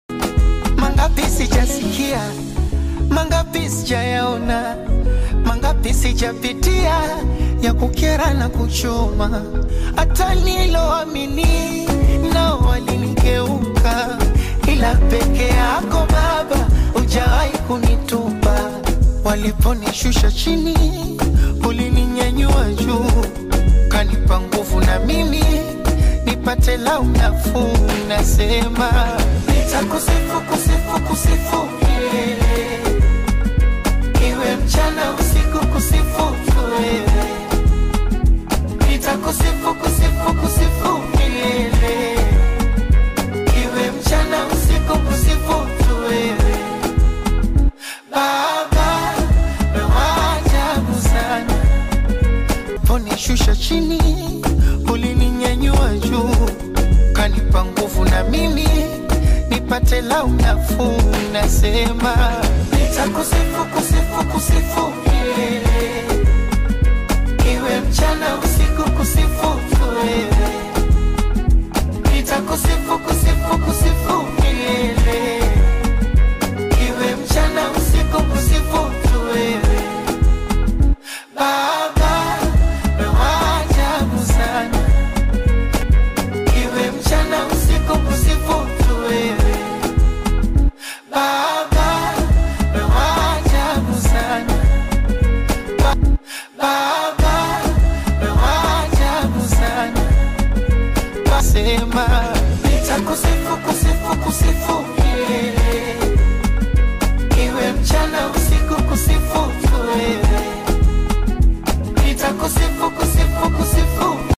gospel-inspired teaser track
Genre: Bongo Flava